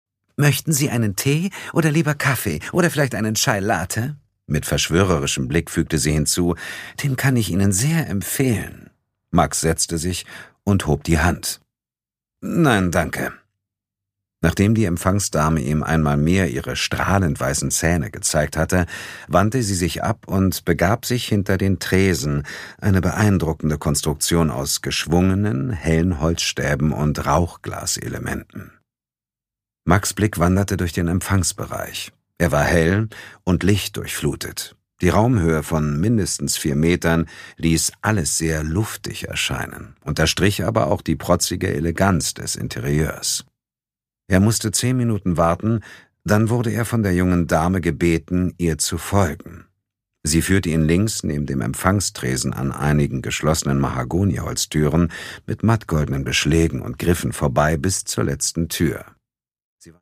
Produkttyp: Hörbuch-Download
Gelesen von: Sascha Rotermund, Dietmar Wunder